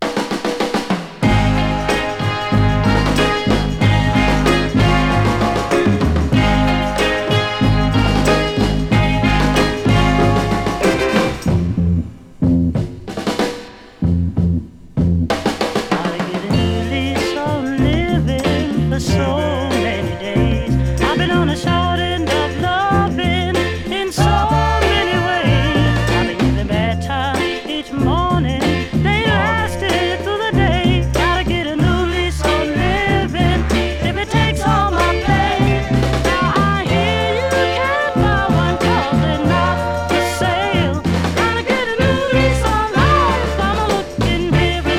Soul　UK　12inchレコード　33rpm　Mono, Stereo